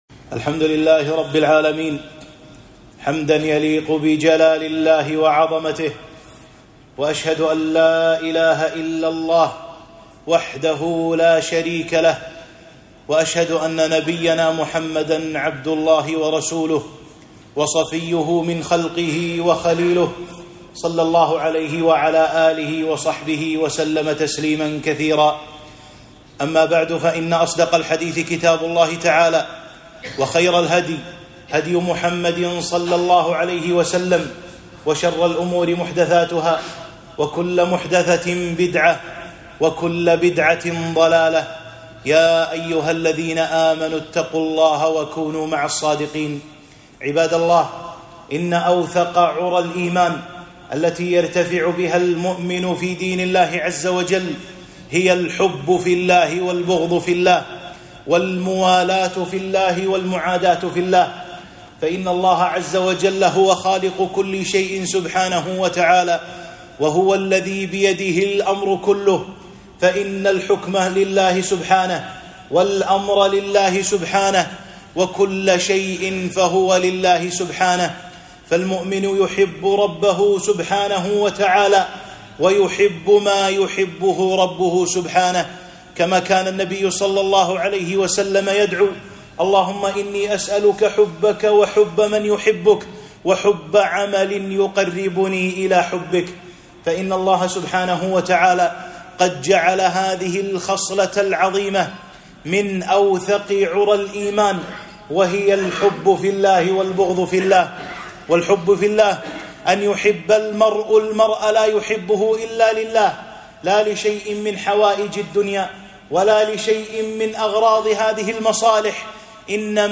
خطبة - الولاء والبراء، والحب في الله، والبغض في الله